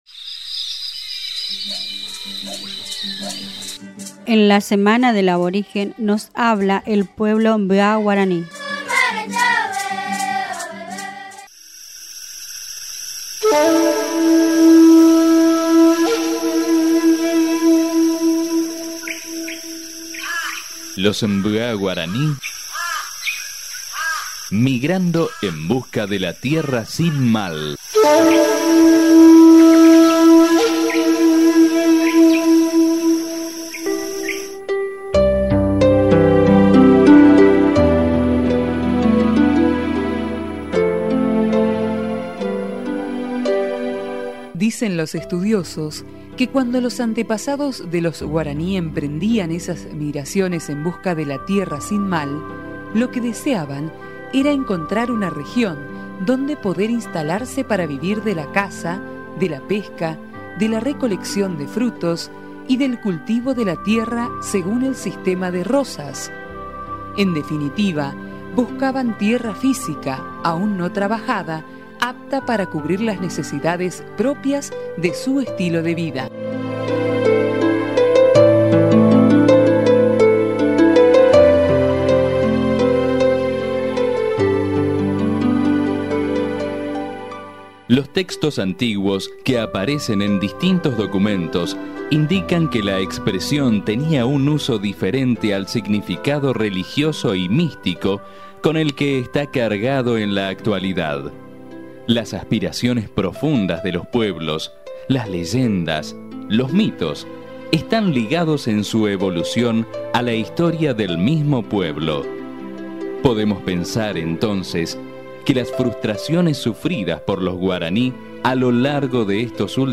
Puede escuchar el audio de un programa radial al respecto.